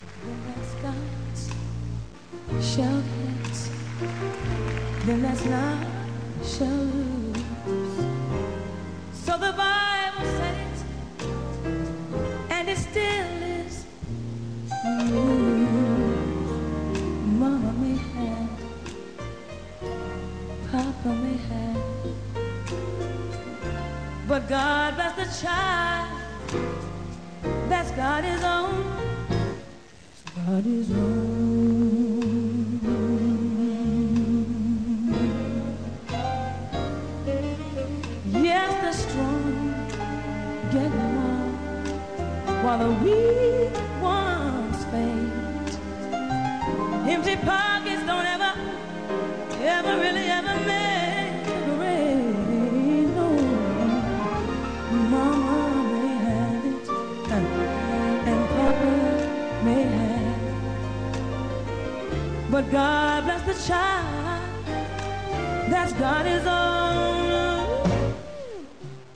Music > Lyrics > Live
Date: October 1997 (live show)